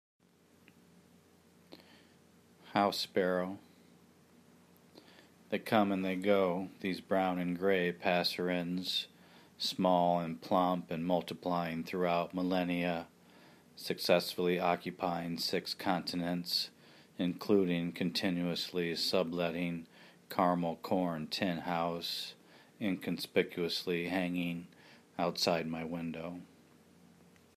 house sparrow
house-sparrow.mp3